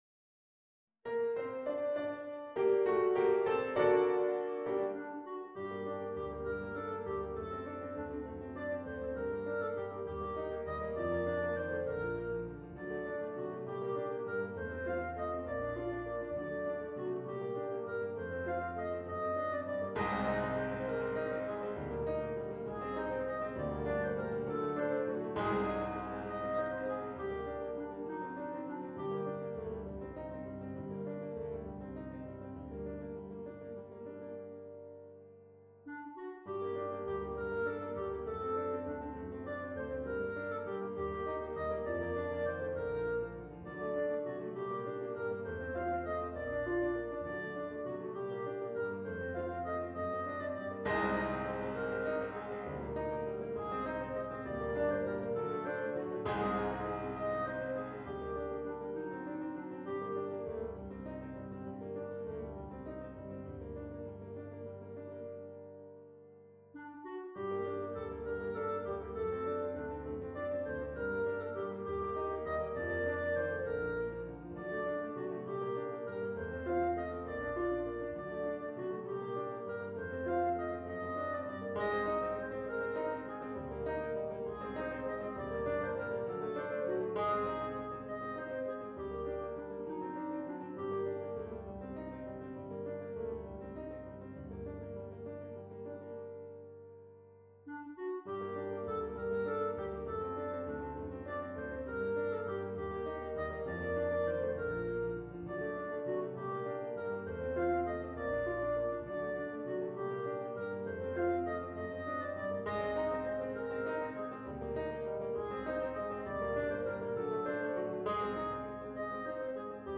This file contains the performance, accompaniment, and sheet music for Bb Clarinet.